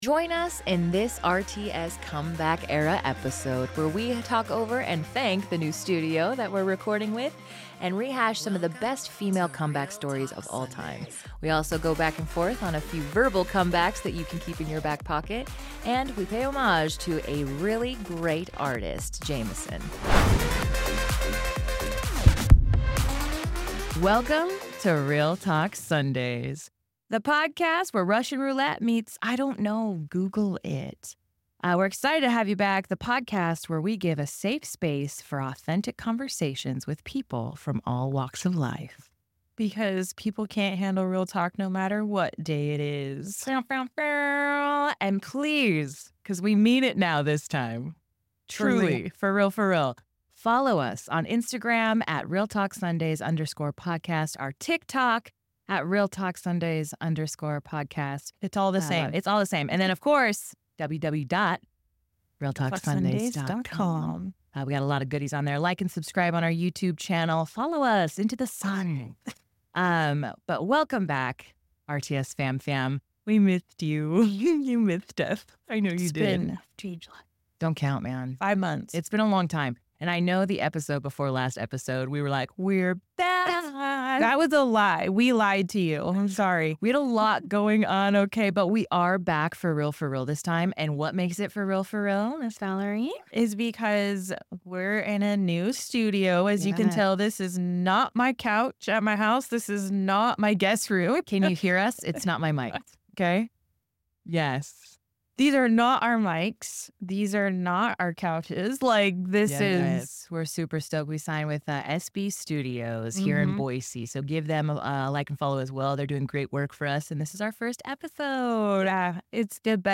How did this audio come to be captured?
We're recording from a new studio and discussing some of the greatest female comeback stories of all time. Plus, get some top-tier verbal comebacks to keep in your back pocket.